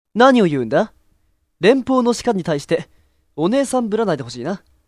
声真似奥義演習場~その弐~
声マネに関しては似てるモン似てねぇモン多々あります。